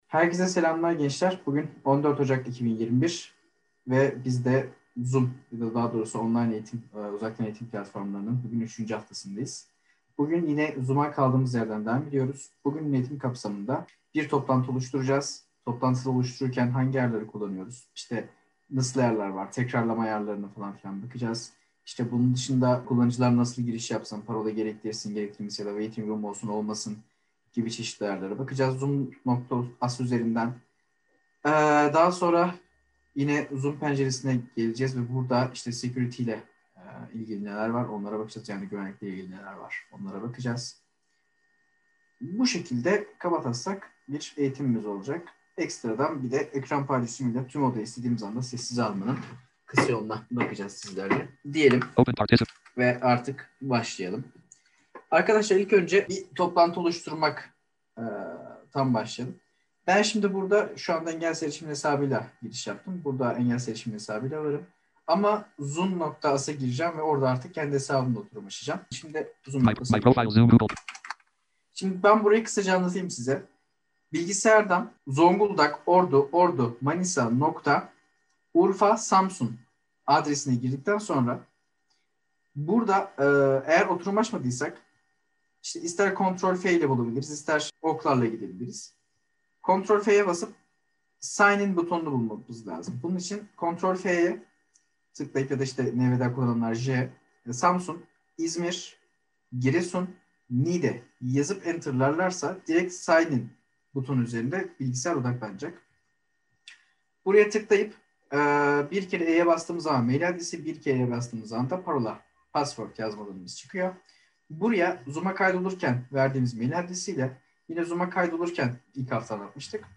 Online Toplantı Platformları Eğitimi